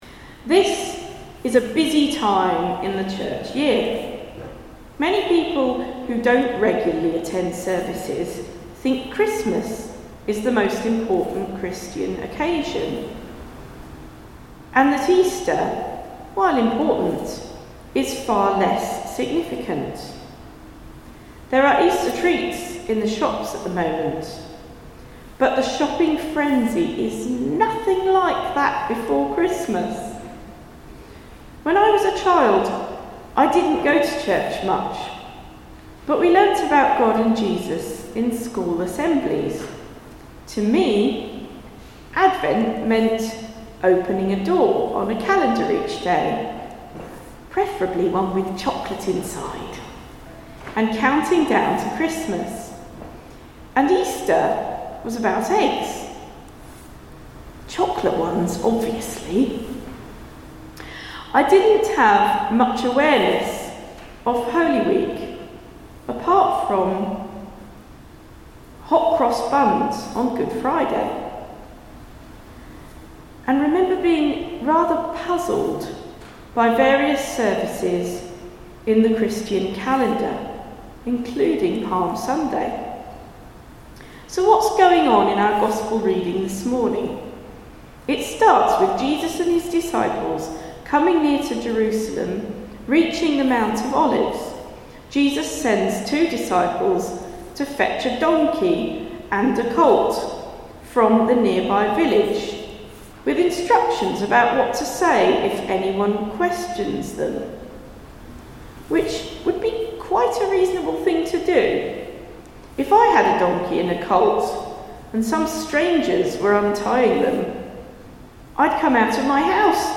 Palm Sunday sermon, preached on 29th March 2026